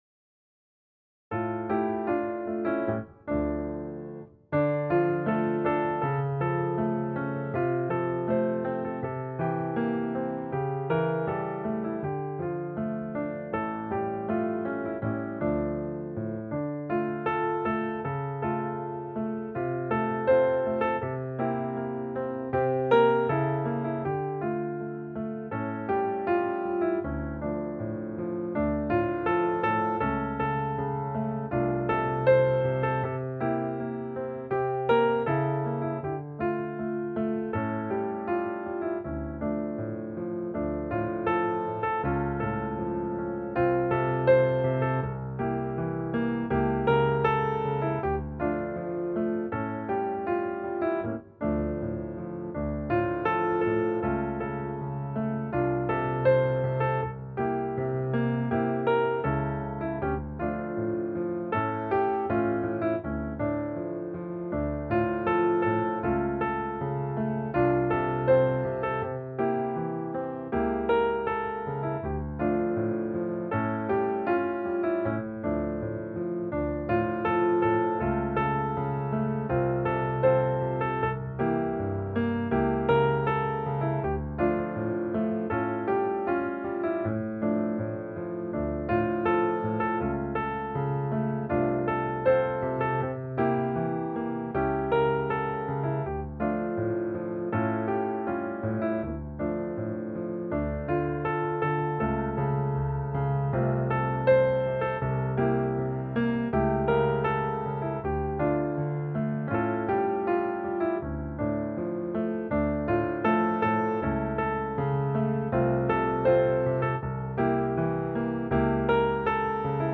akompaniament